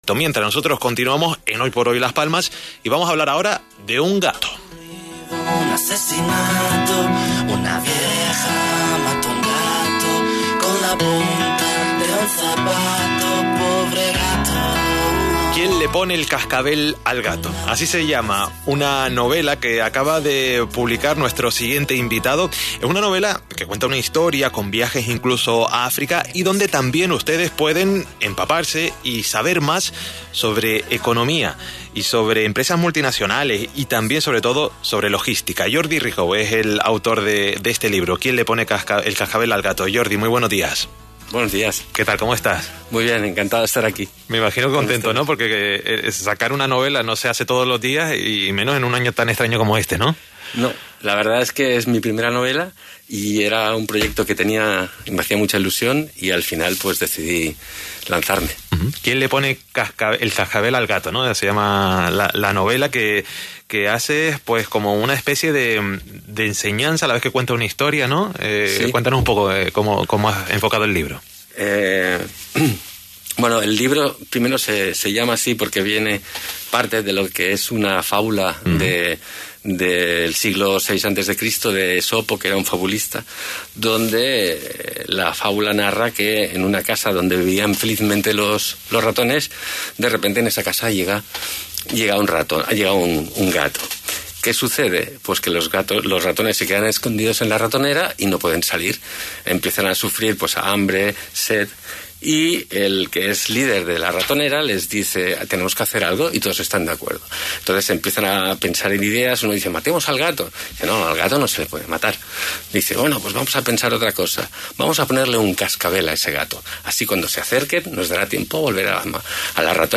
Entrevista Cadena Ser Las Palmas de Gran Canaria (Foto)
Entrevista-en-la-cadena-Ser.mp3